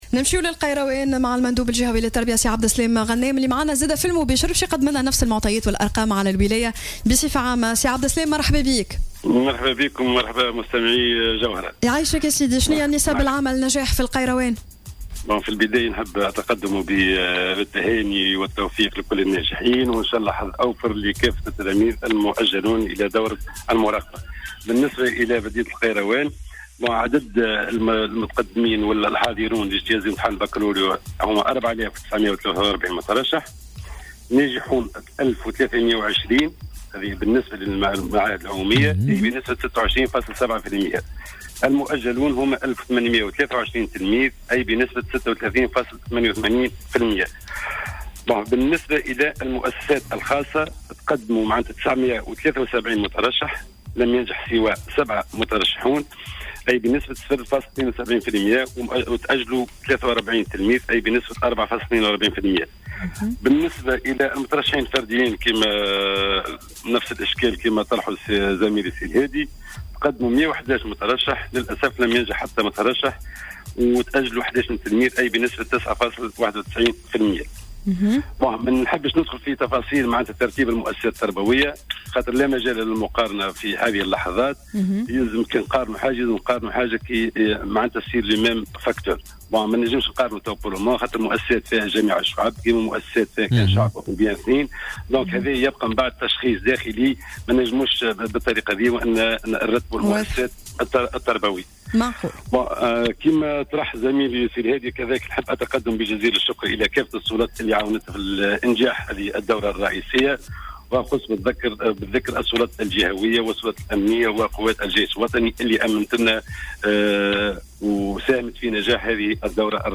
أكد المندوب الجهوي للتربية بالقيروان عبد السلام غنام في مداخلة له في صباح الورد صباح اليوم الجمعة 23 جوان 2017 أن نسبة النجاح العامة في الدورة الرئيسية لامتحان الباكالوريا أن نسبة النجاح في المعاهد العمومية بلغت 26.7 بالمائة.